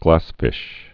(glăsfĭsh)